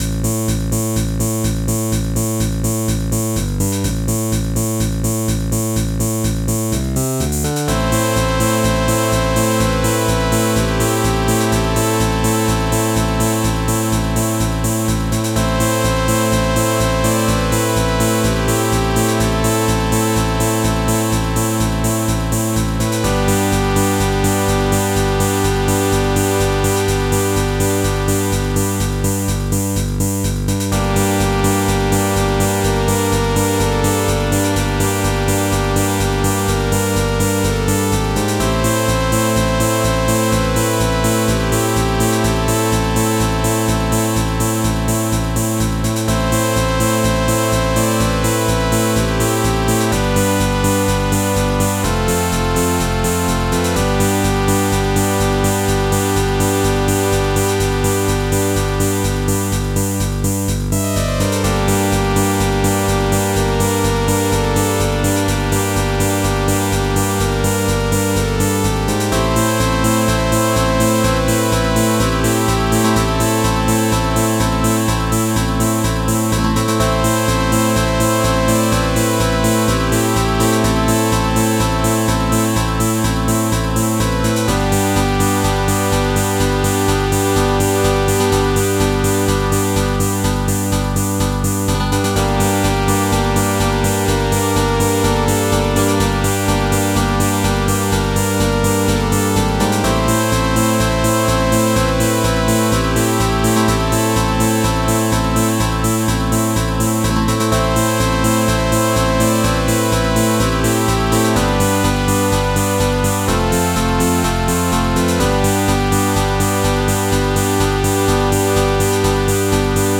Style: Game